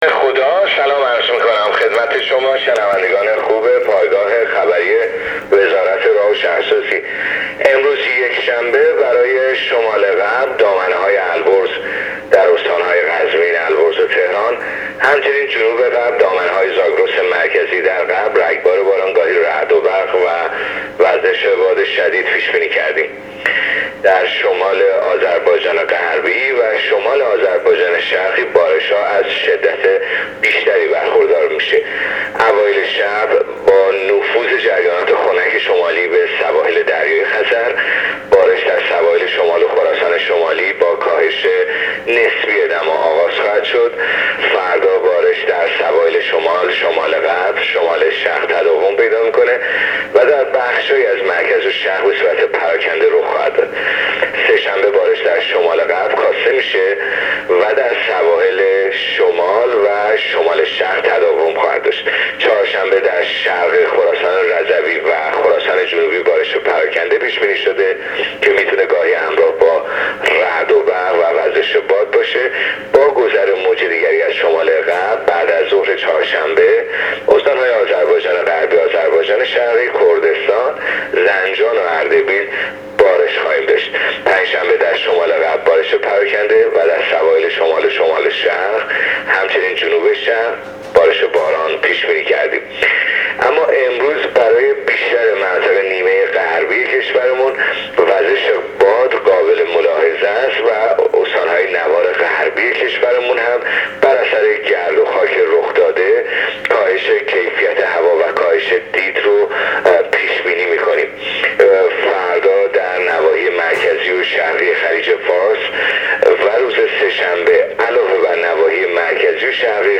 گزارش رادیو اینترنتی از آخرین وضعیت آب و هوای ۲۱ اردیبهشت ۱۳۹۹